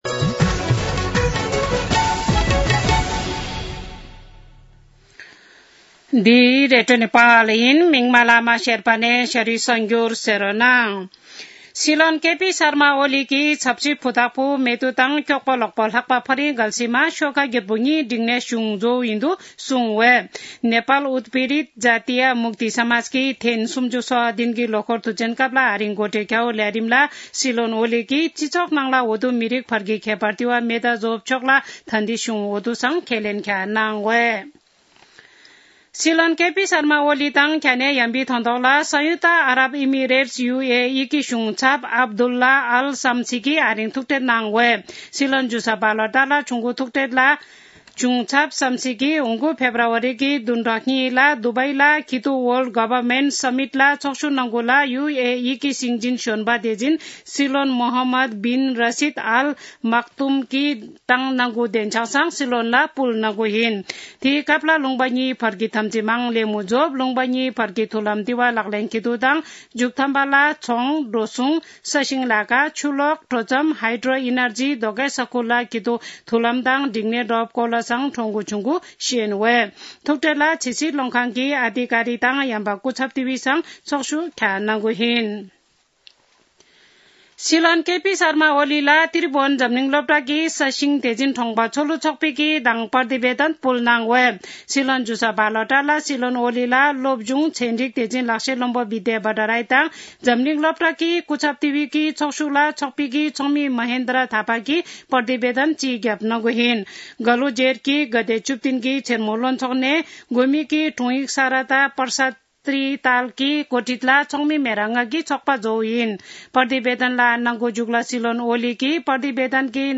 शेर्पा भाषाको समाचार : ४ पुष , २०८१
4-pm-Sherpa-news.mp3